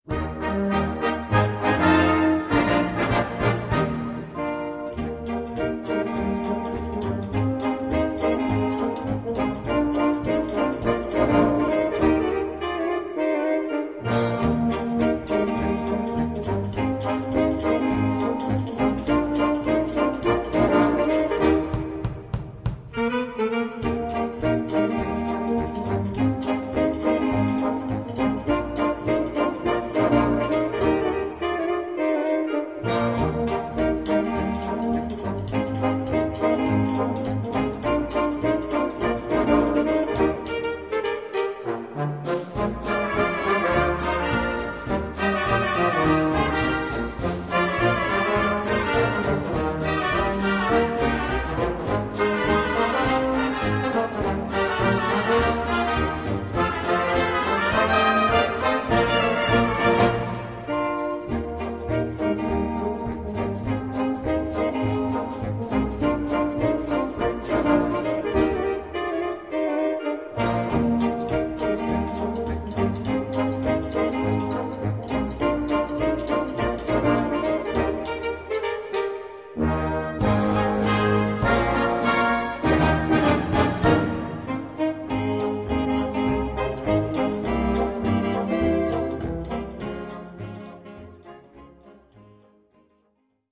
Gattung: Solo für drei Saxophone und Blasorchester
Besetzung: Blasorchester
swingende